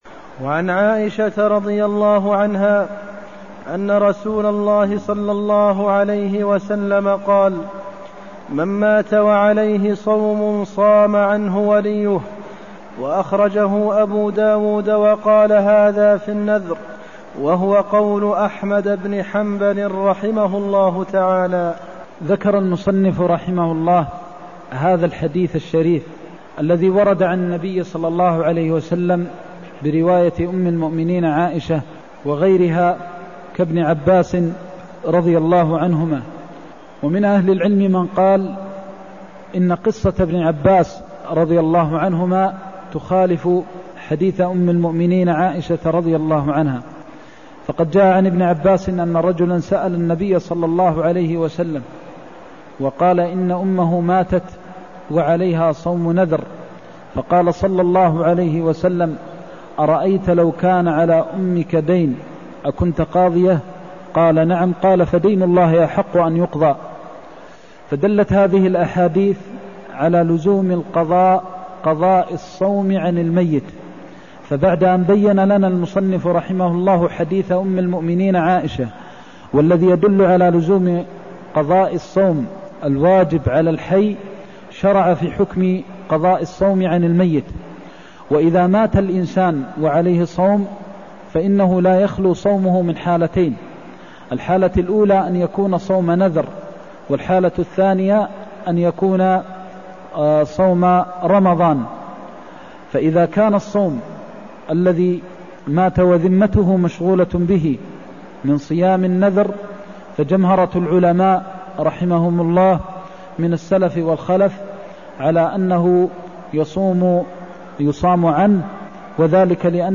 المكان: المسجد النبوي الشيخ: فضيلة الشيخ د. محمد بن محمد المختار فضيلة الشيخ د. محمد بن محمد المختار من مات وعليه صيام صام عنه وليه (183)